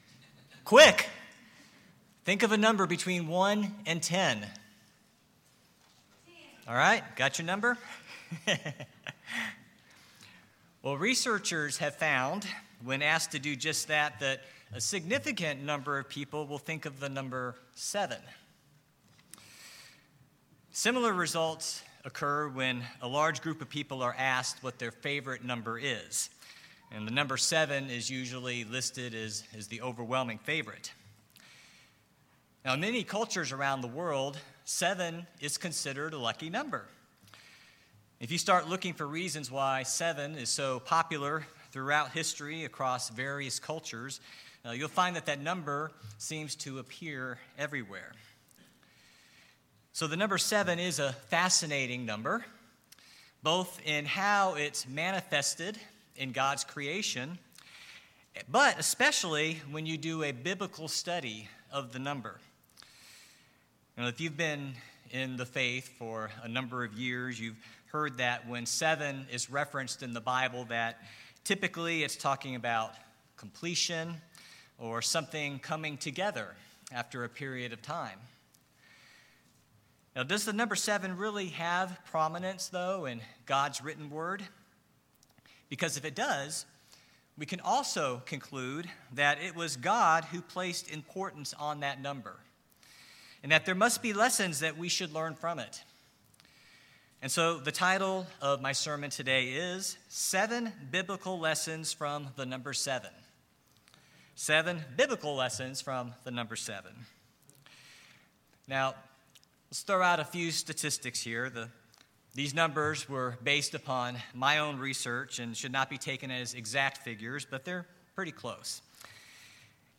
Sermons
Given in East Texas